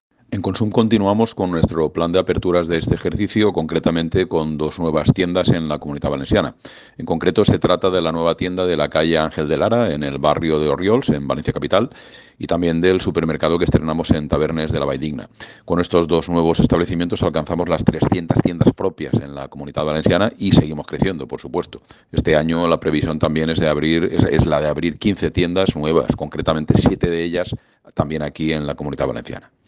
Corte de voz